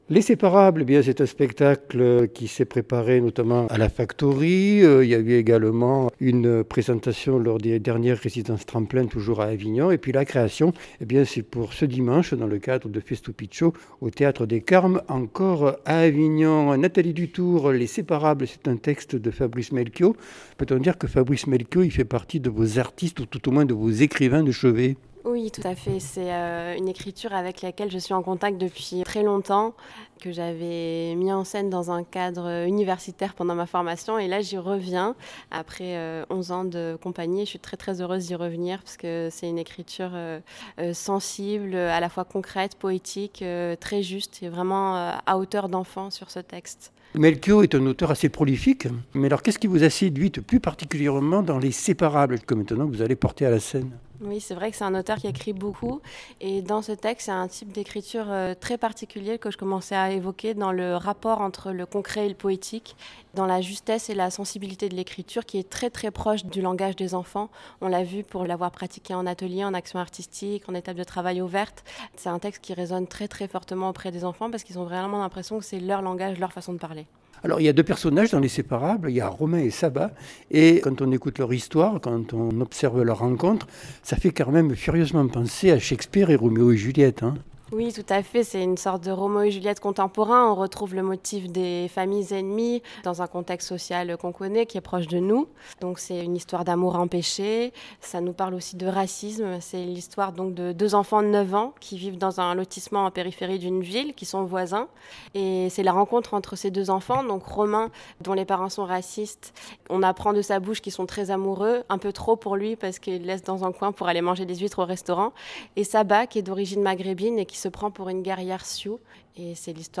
Interview ICI Vaucluse - 3/4/25